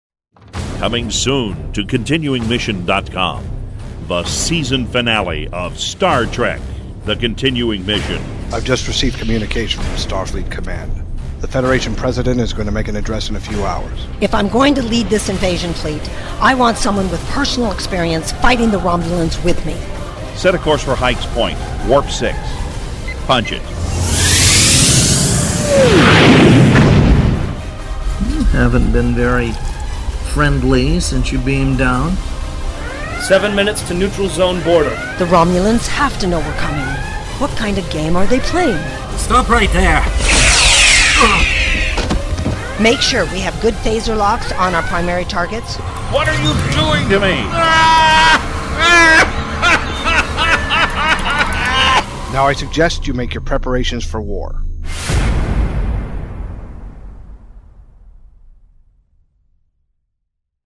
These are the Voyages… for “Star Trek” fans, the voyages go on in the form of an internationally produced, fan audio drama series called “Star Trek: The Continuing Mission”.
Trailer for “Star Trek: The Continuing Mission” Season Finale “We Will Control All That You See and Hear”